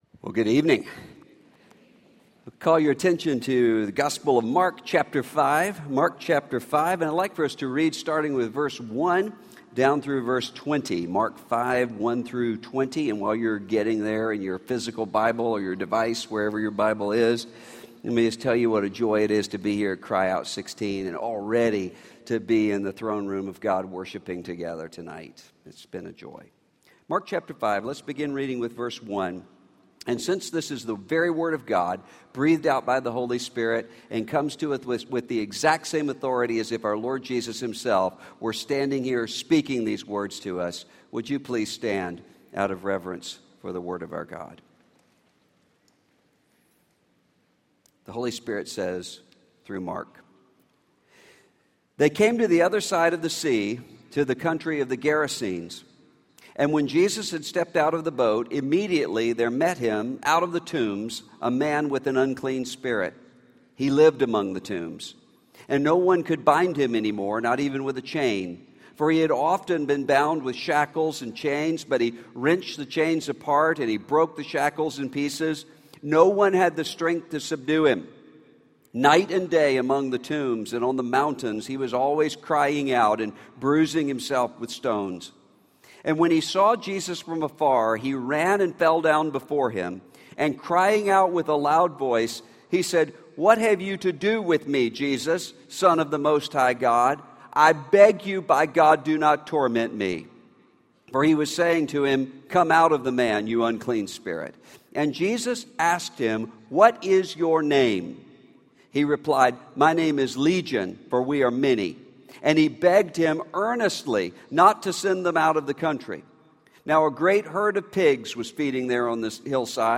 In this opening message from True Woman ’16, Russell Moore shows that as believers, we often despair because we have forgotten who we are. Learn how to overcome fear and panic with the confidence that only comes from Christ.